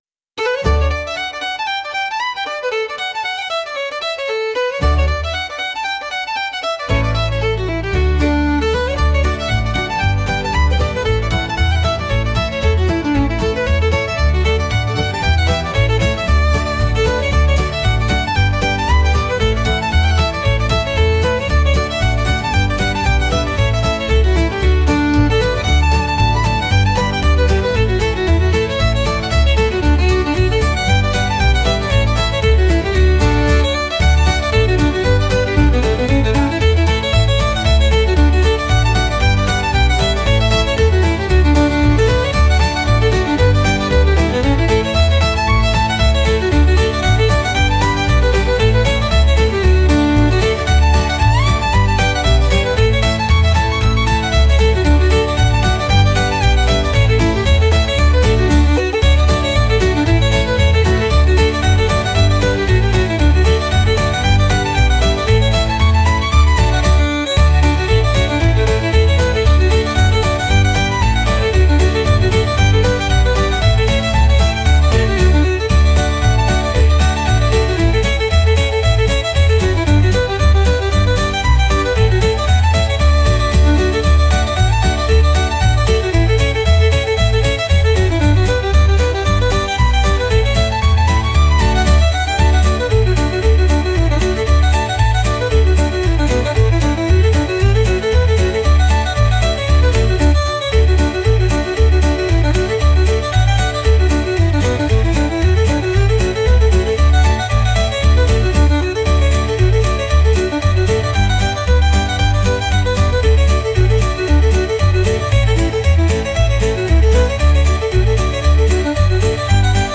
花を持ってうきうきしているようなケルト曲です。